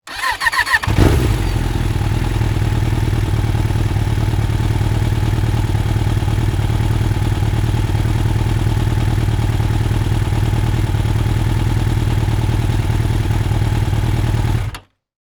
motorcycle-start-and-idle-03.wav